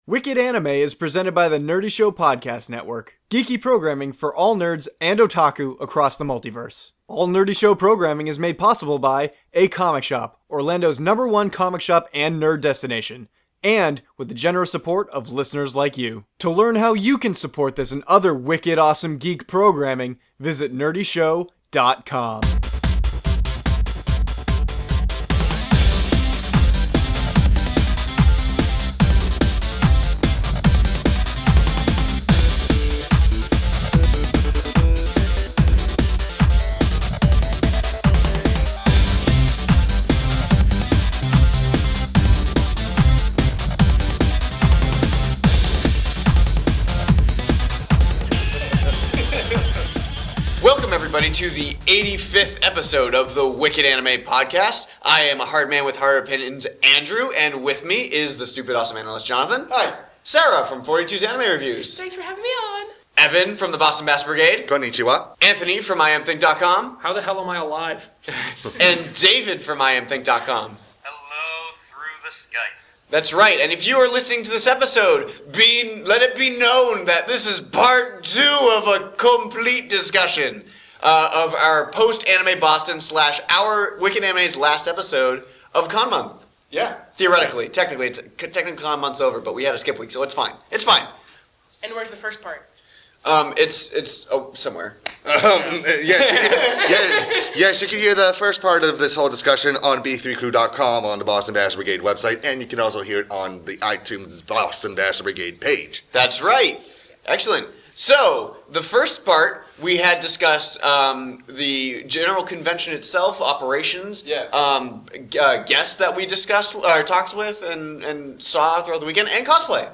As we recorded this episode, the Hynes Convention Center was being emptied of its guests.